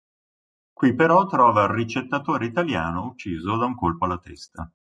Read more Verb Noun Frequency A1 Hyphenated as uc‧cì‧so Pronounced as (IPA) /utˈt͡ʃi.zo/ Etymology From the verb uccidere, Latin occisus.